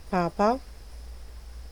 Ääntäminen
IPA: [pap]